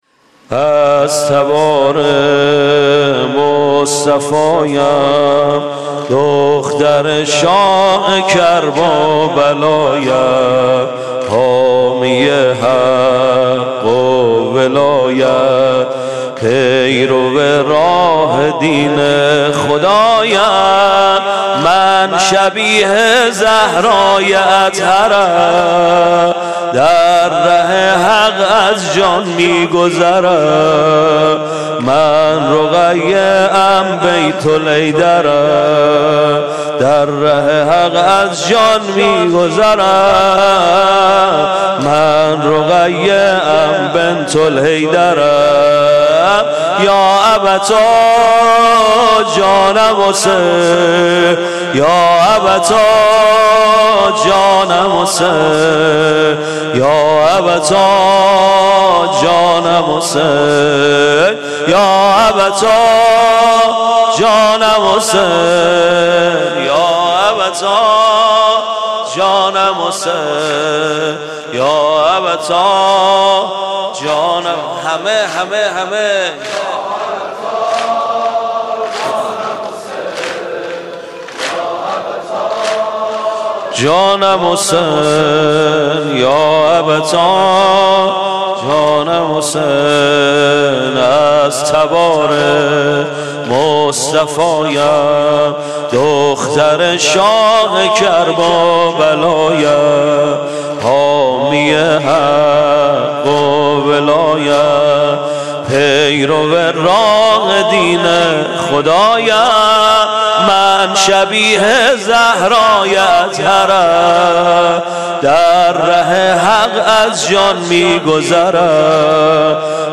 مراسم شب سوم دهه اول محرم
سینه زنی